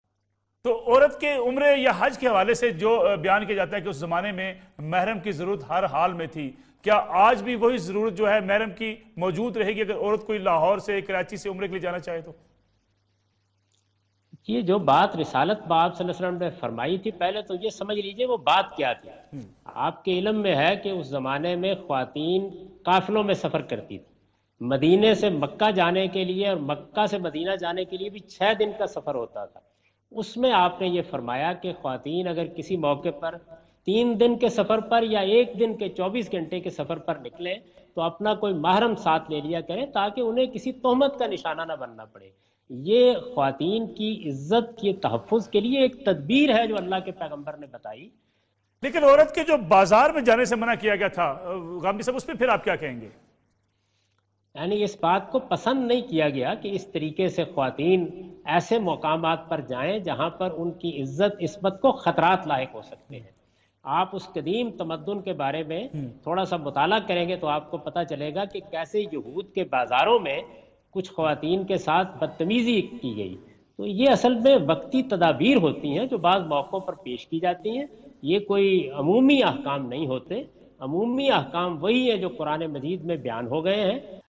Category: TV Programs / Neo News /
In this program Javed Ahmad Ghamidi answer the question about "Travelling of Women without Mahram" on Neo News.